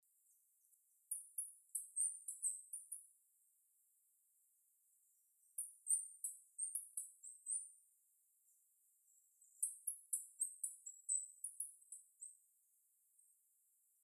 Cerquero de Collar Yungueño (Arremon dorbignii)
Nombre en inglés: Moss-backed Sparrow
Condición: Silvestre
Certeza: Fotografiada, Vocalización Grabada